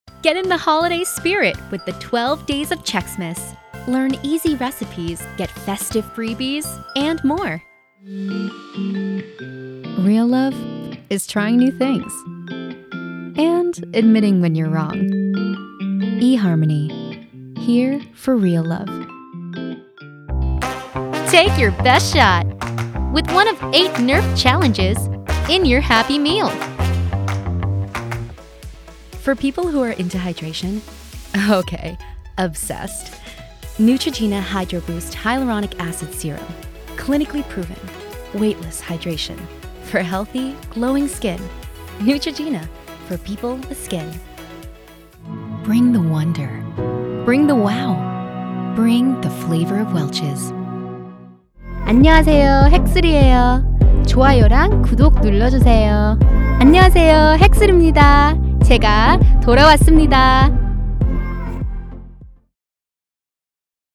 Voiceover : Commercial : Women